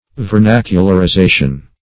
Meaning of vernacularization. vernacularization synonyms, pronunciation, spelling and more from Free Dictionary.
Search Result for " vernacularization" : The Collaborative International Dictionary of English v.0.48: Vernacularization \Ver*nac"u*lar*i*za"tion\, n. The act or process of making vernacular, or the state of being made vernacular.